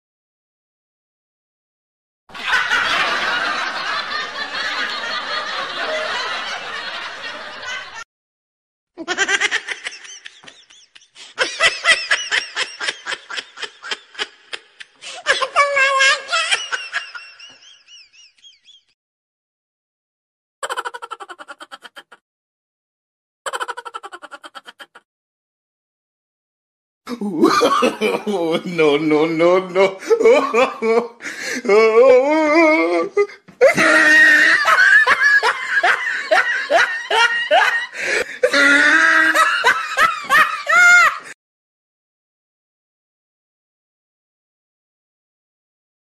Suara Ketawa Viral
Kategori: Suara ketawa
Efek suara ini cocok banget buat nambahin suasana seru dan lucu di konten kalian.
suara-ketawa-viral-id-www_tiengdong_com.mp3